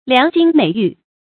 良金美玉 注音： ㄌㄧㄤˊ ㄐㄧㄣ ㄇㄟˇ ㄧㄩˋ 讀音讀法： 意思解釋： 比喻文章十分完美。